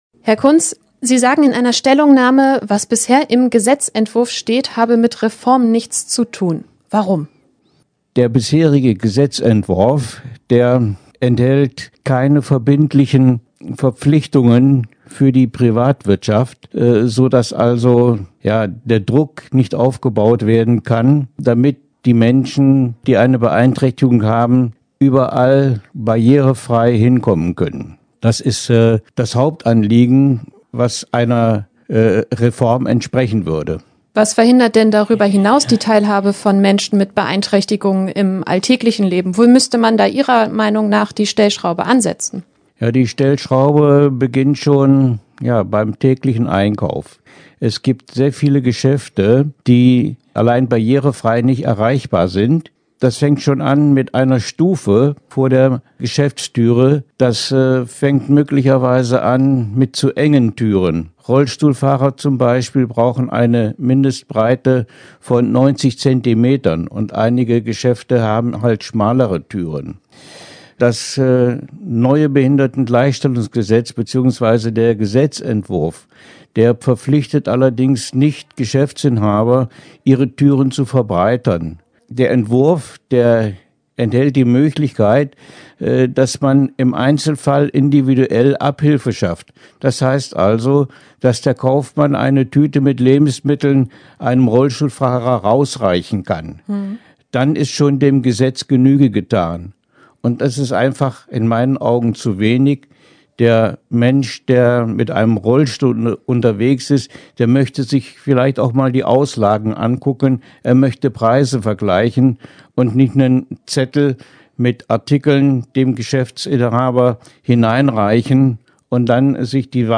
Int-Behindertengleichstellungsgesetz.mp3